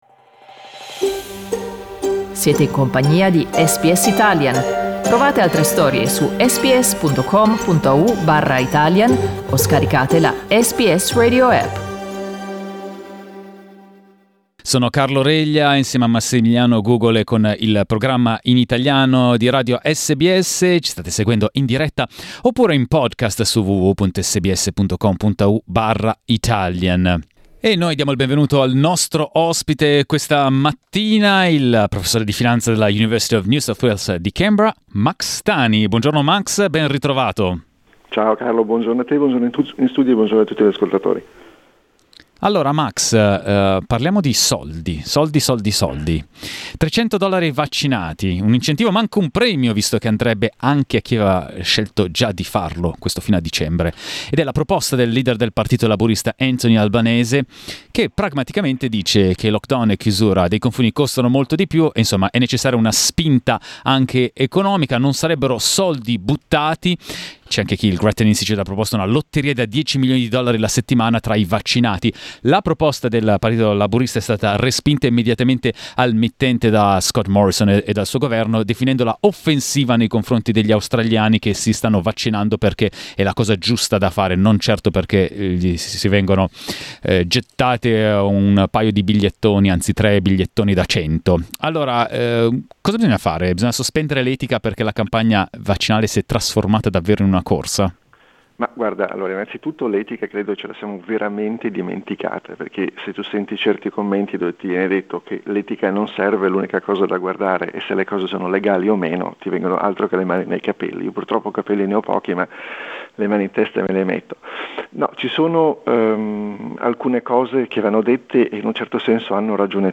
Ascolta l’intervista: LISTEN TO L'opposizione propone incentivi economici per incoraggiare a vaccinarsi SBS Italian 10:56 Italian Le persone in Australia devono stare ad almeno 1,5 metri di distanza dagli altri.